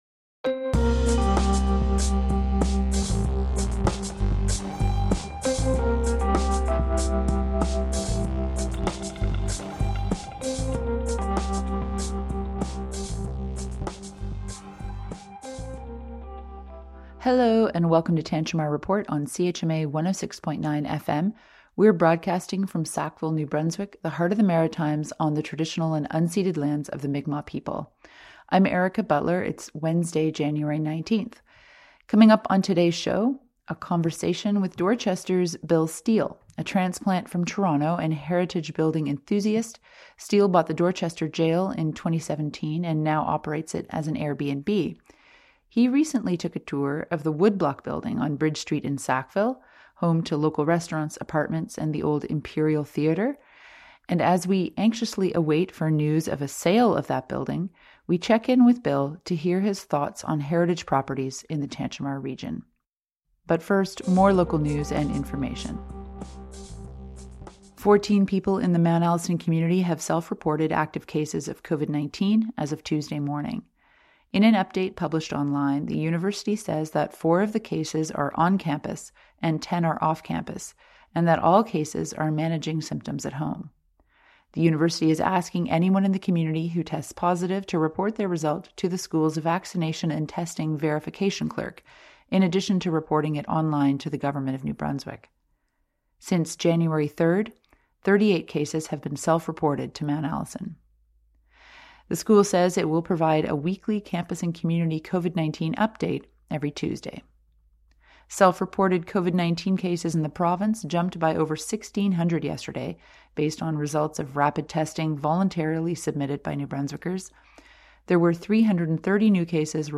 Plus, more local news and information